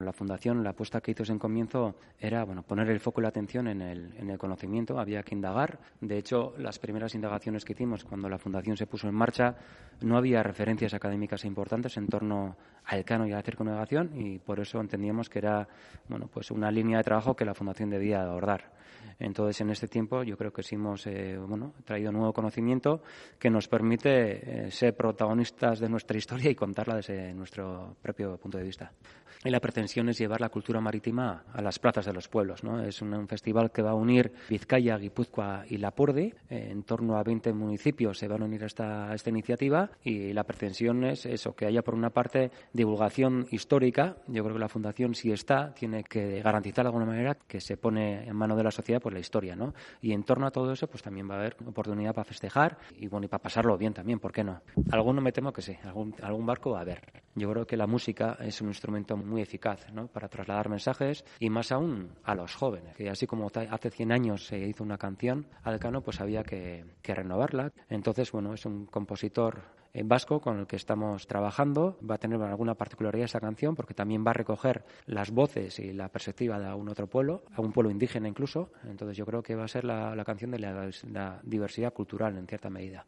Y la entrevista completa en Gipuzkoako Kale Nagusia en nuestro podcast